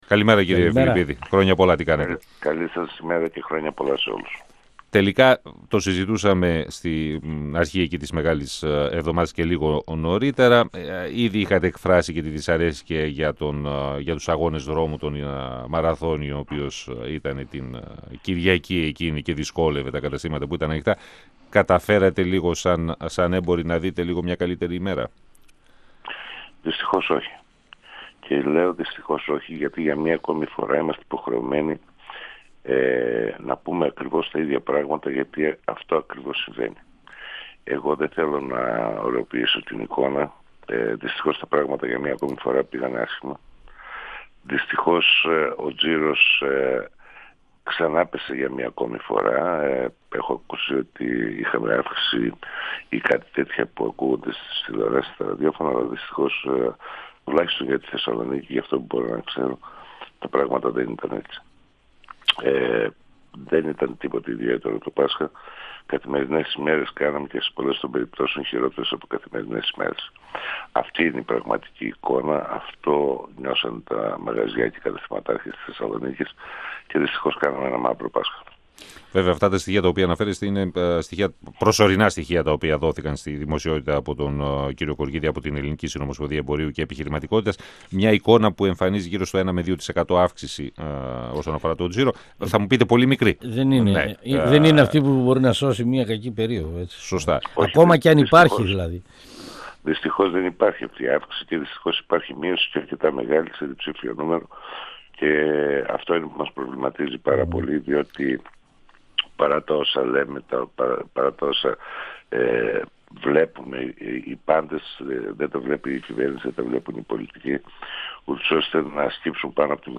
Συνέντευξη